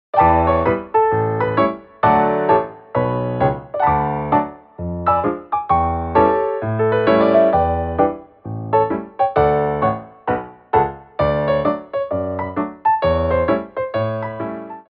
MEDIUM TEMPO